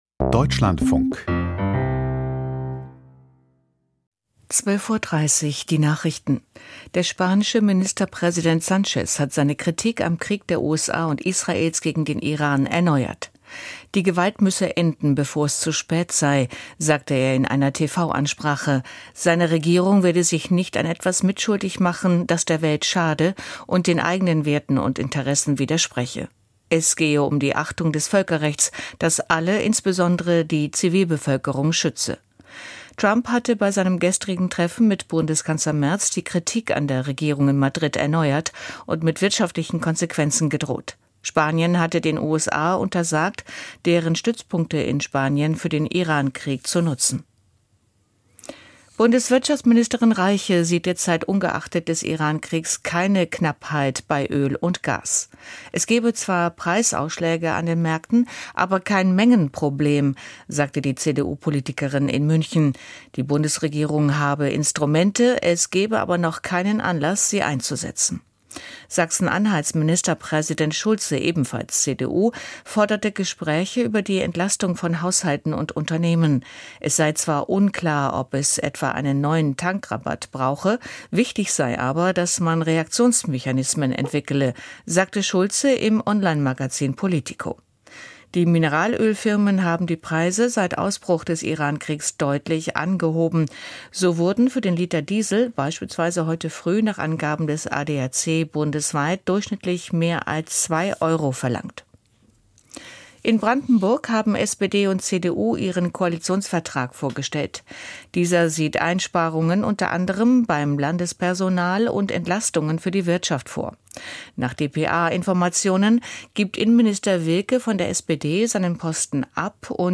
Die Nachrichten vom 04.03.2026, 12:30 Uhr
Aus der Deutschlandfunk-Nachrichtenredaktion.